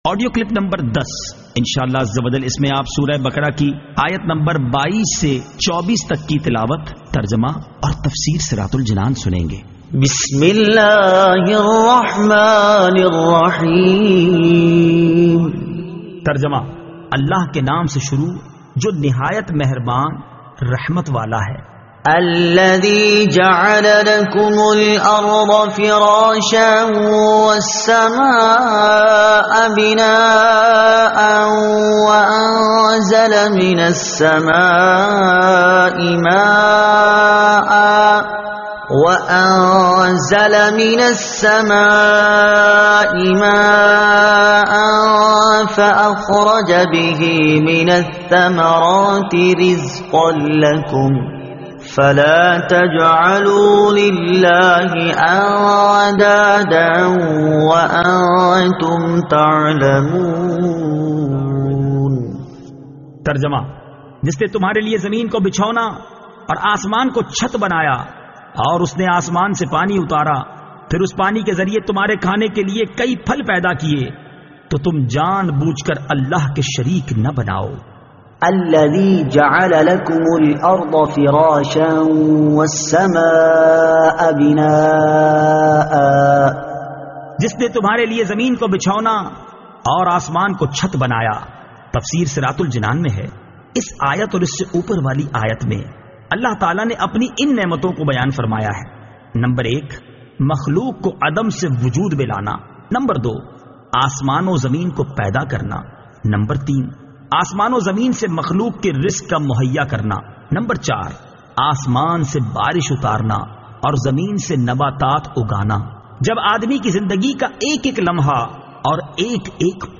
Surah Al-Baqara Ayat 22 To 24 Tilawat , Tarjuma , Tafseer